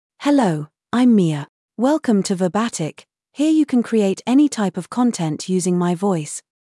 Mia — Female English (United Kingdom) AI Voice | TTS, Voice Cloning & Video | Verbatik AI
FemaleEnglish (United Kingdom)
Mia is a female AI voice for English (United Kingdom).
Voice sample
Mia delivers clear pronunciation with authentic United Kingdom English intonation, making your content sound professionally produced.